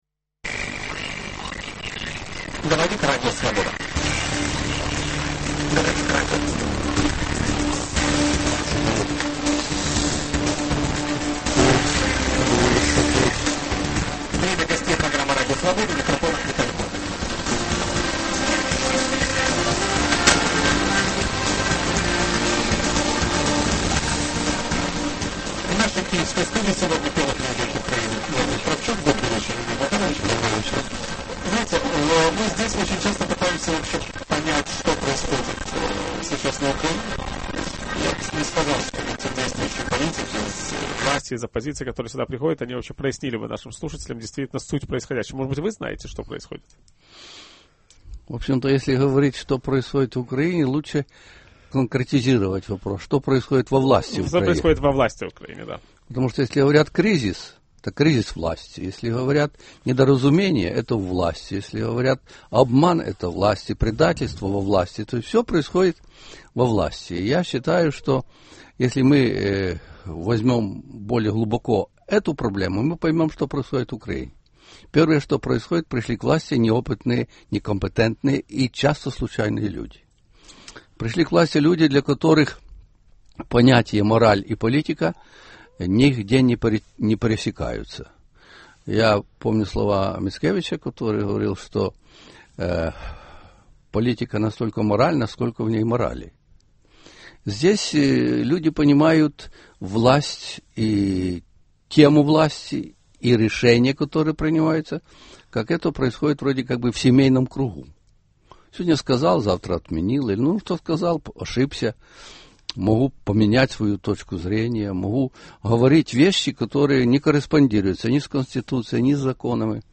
О украинской политической ситуации и российско-украинских отношениях ведущий программы Виталий Портников беседует с первым президентом Украины Леонидом Кравчуком.